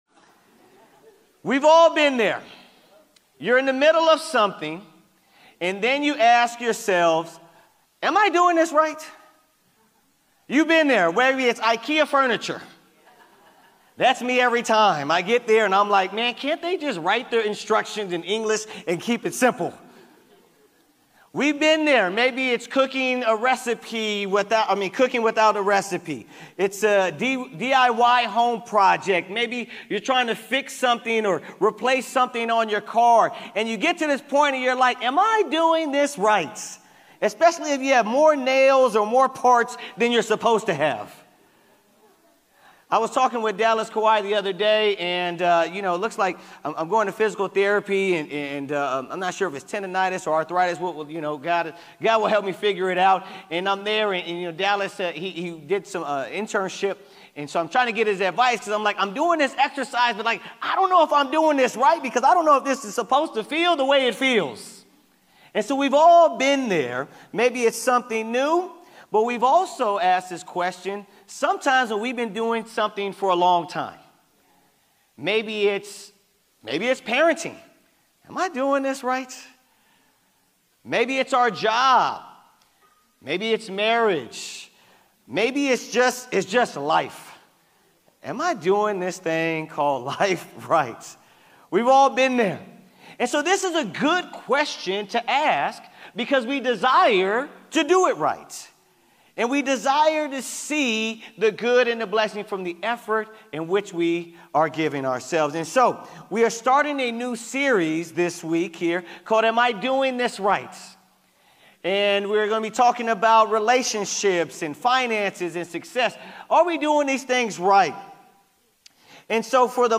OC Church of Christ Sermons